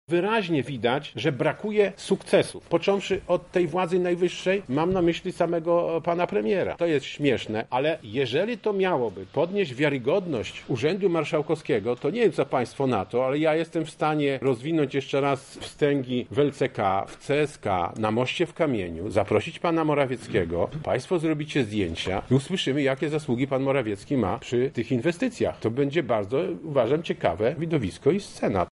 Na liście znajdują się między innymi rozbudowa Centrum Onkologii Ziemi Lubelskiej czy budowa dróg ekspresowych – otwartej w środę, 22 sierpnia przez premiera Morawieckiego S12 na odcinku obwodnicy Puław – mówi marszałek Sławomir Sosnowski: